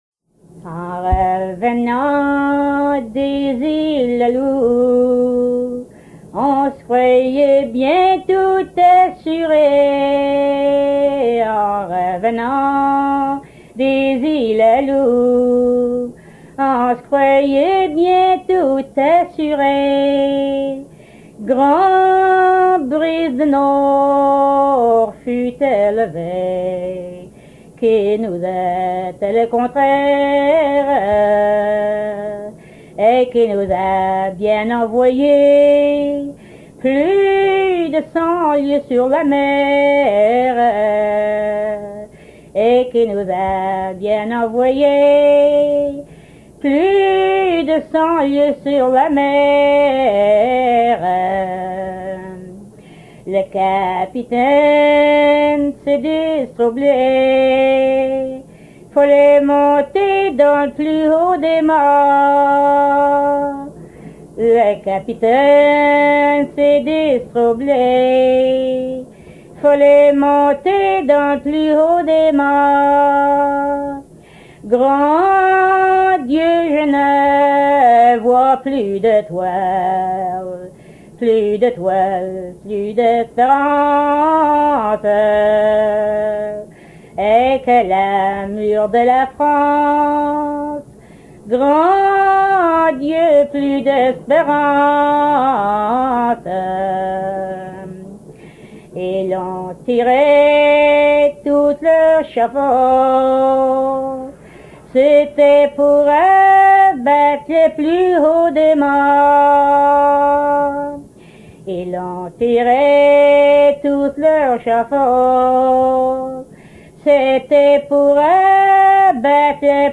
Enregistré à Degras, Cap-Saint-Georges, île de Terre-neuve le 16 juin 1973
Genre strophique